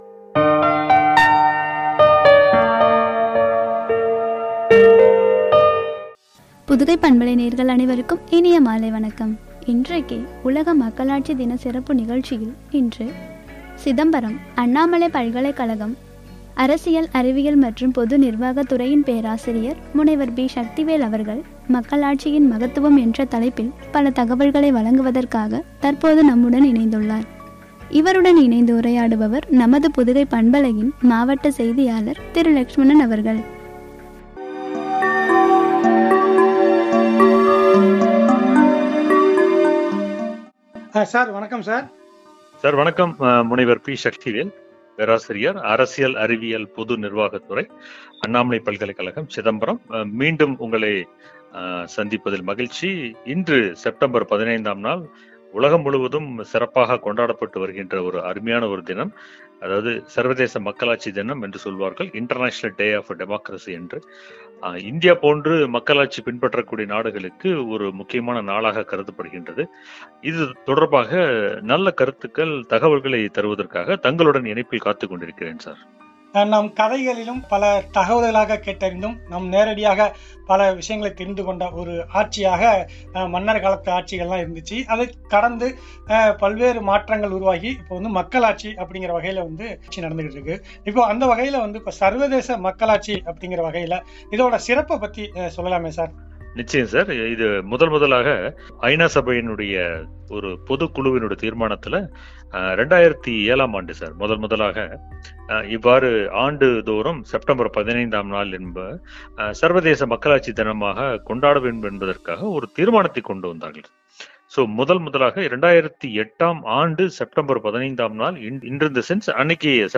வழங்கிய உரையாடல்.